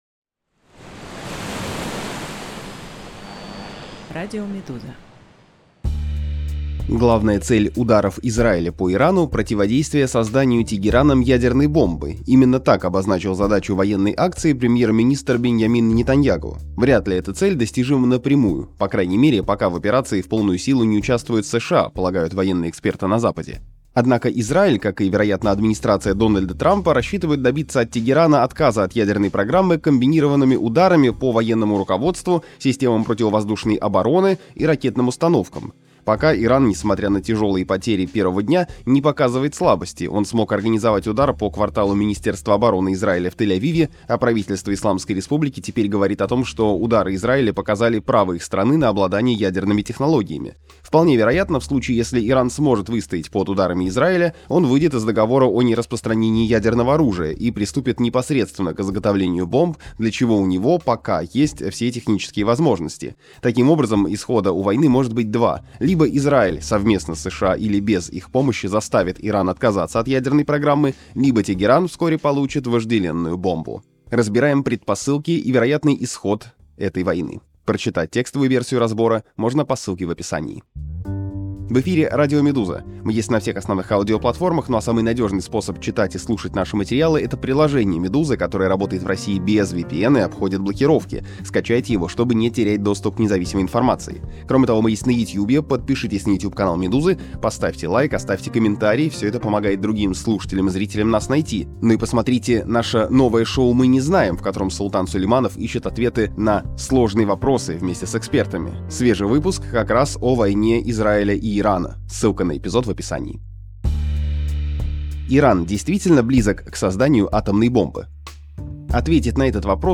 Предпосылки и возможный итог войны Израиля и Ирана. Аудиоверсия разбора